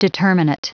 Prononciation du mot determinate en anglais (fichier audio)
Prononciation du mot : determinate